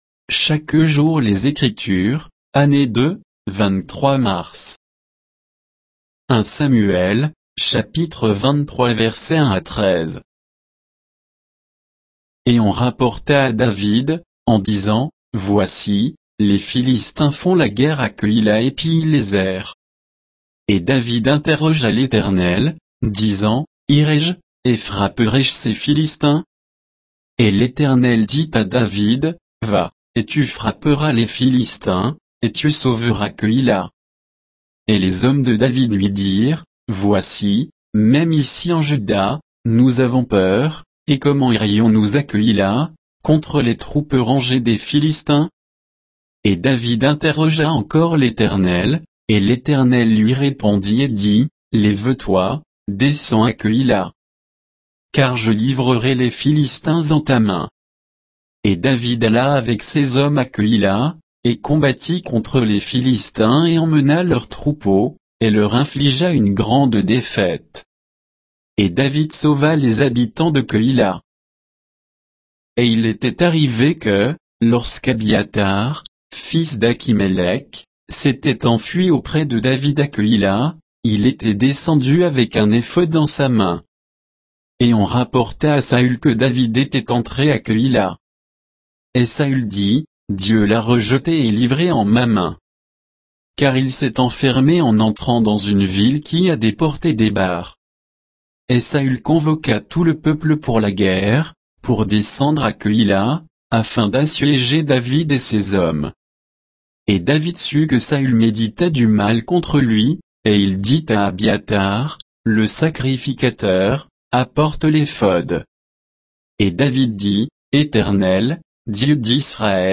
Méditation quoditienne de Chaque jour les Écritures sur 1 Samuel 23